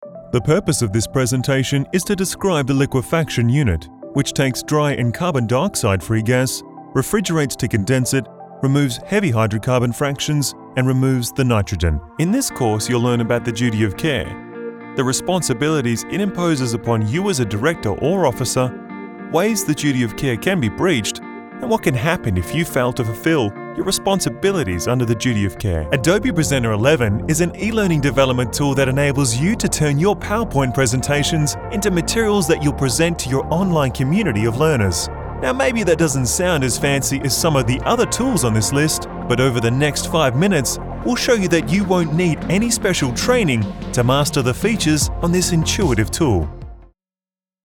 Professional home studio with treated sound booth and source connect.
English Voice Actor, clients Microsoft, A.Schulman, XBOX Warm authentic voice
englisch (australisch)
Sprechprobe: eLearning (Muttersprache):